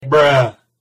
Bruh Sound Effect Free Download